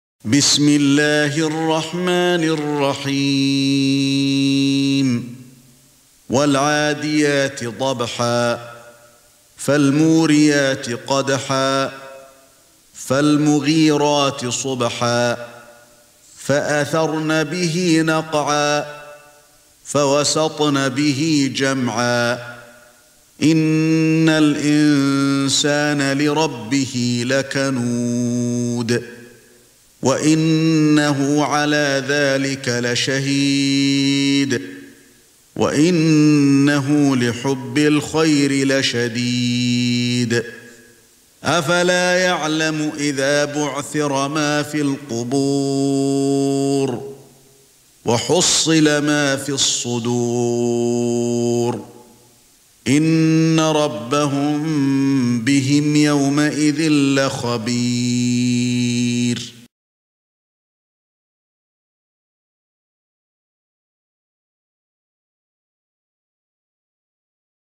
سورة العاديات ( برواية قالون ) > مصحف الشيخ علي الحذيفي ( رواية قالون ) > المصحف - تلاوات الحرمين